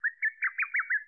Sound Effects
Zelda BOTW Bird Chirping